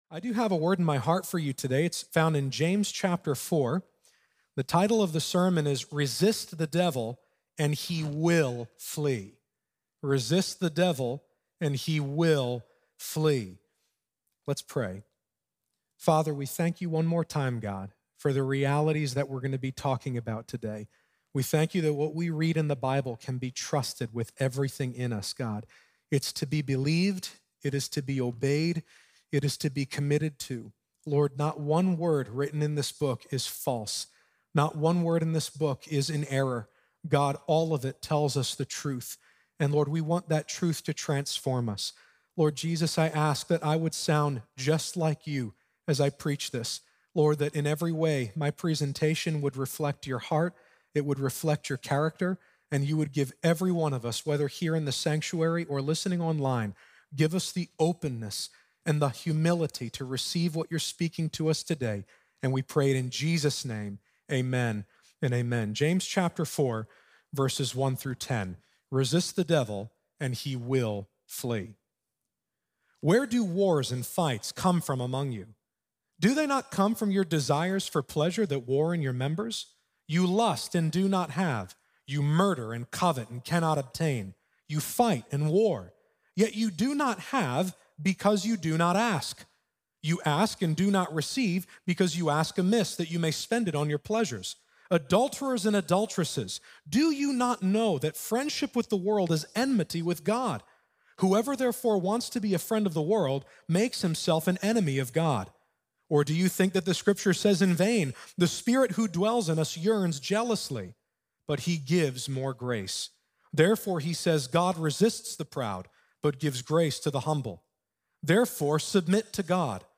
Resist The Devil And He Will Flee | Times Square Church Sermons